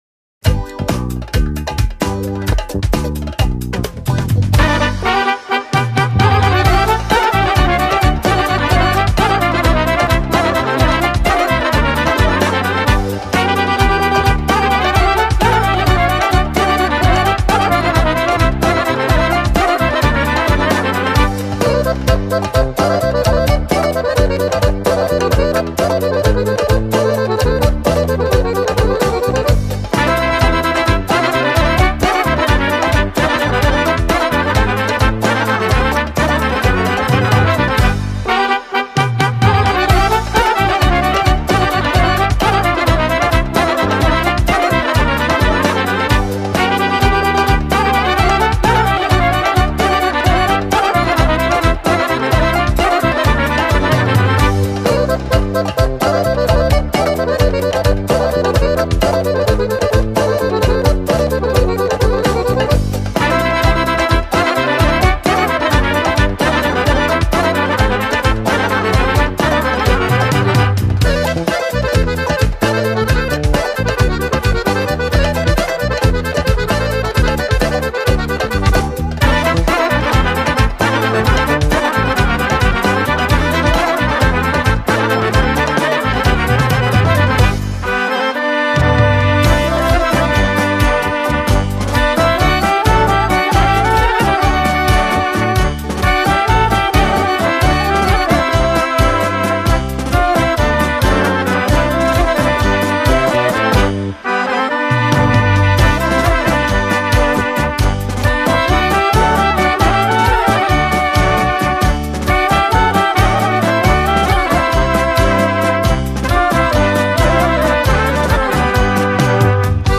Оваа аудио снимка претставува авторско музичко остварување – оро со наслов „Владимирчево оро“